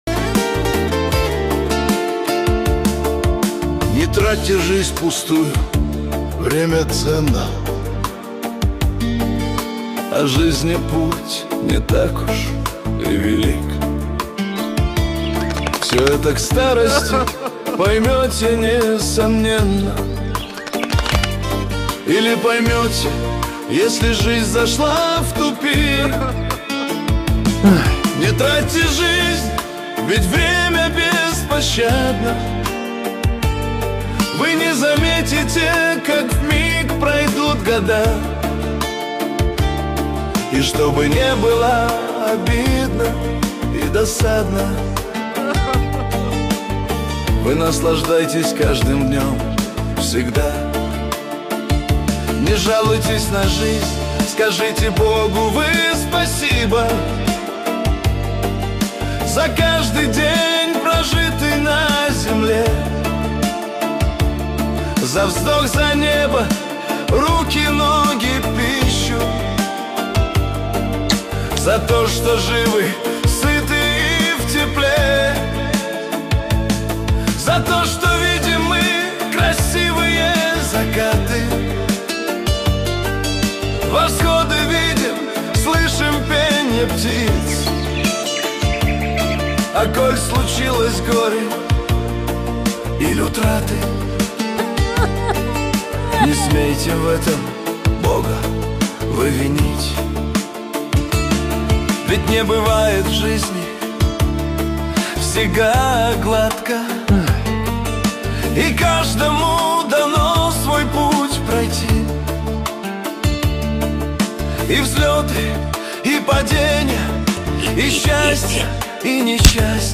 Шансон для души.